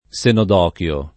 senodochio [ S enod 0 k L o ]